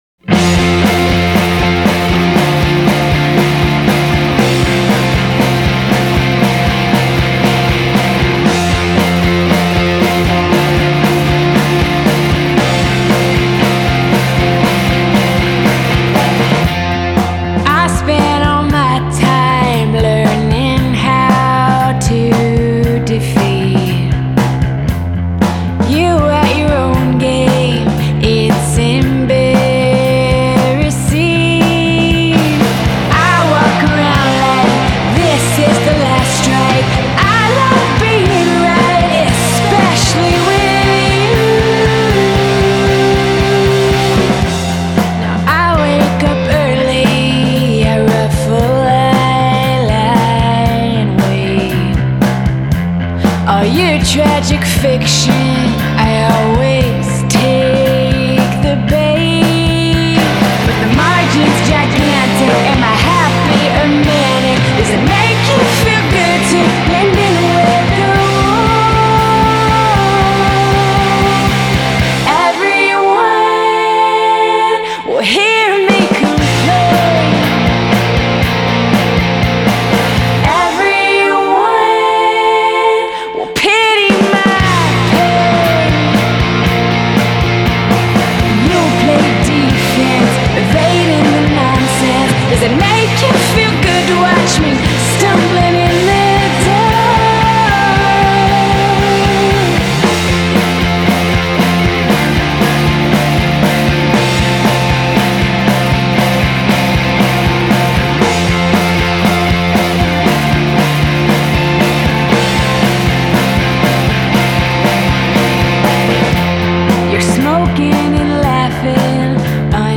a more aggressive, guitar-oriented affair